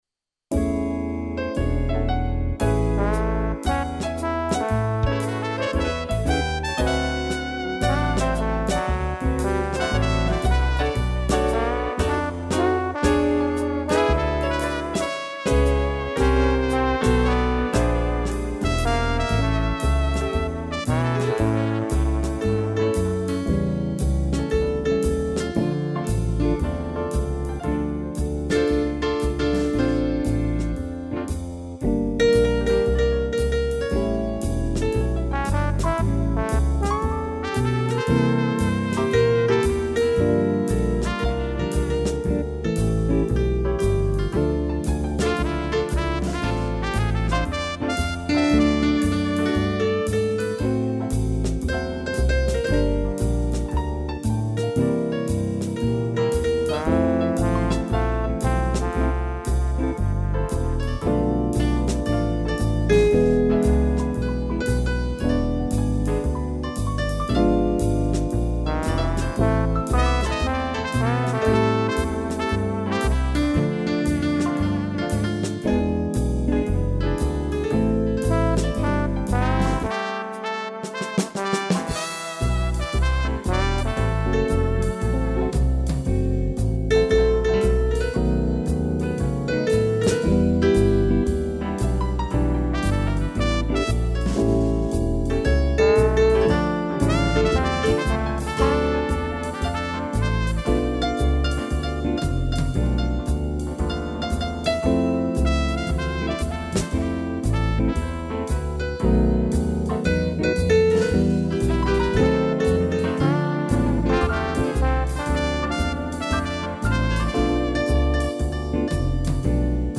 piano, trombone, trompete